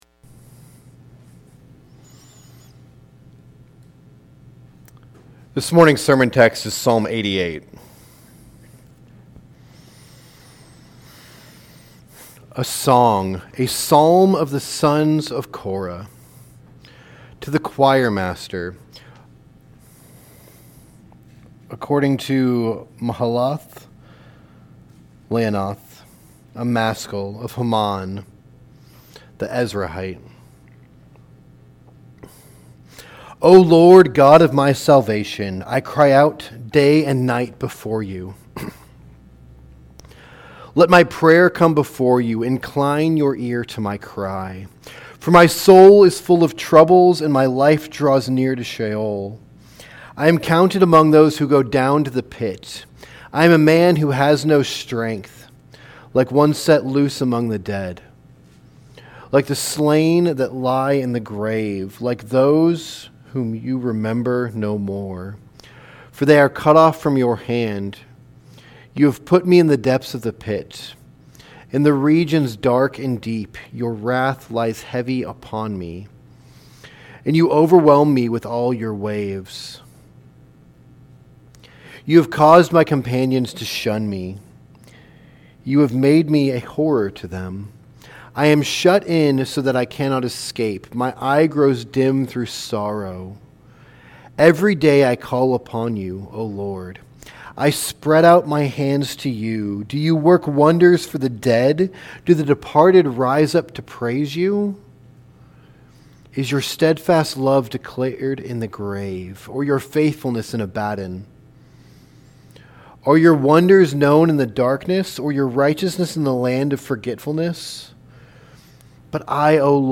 Sermons | Maranatha Baptist Church